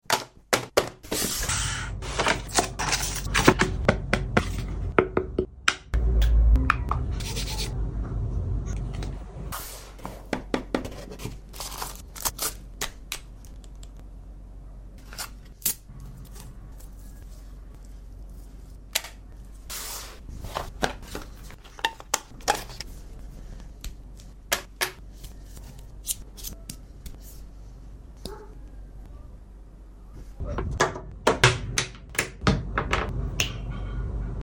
Unboxing some sleek new pieces sound effects free download